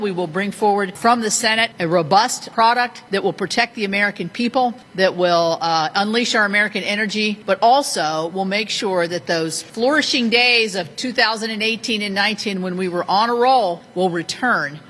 Republican Committee Chair Shelly Moore-Capito told reporters she is predicting success with bill reconciliation…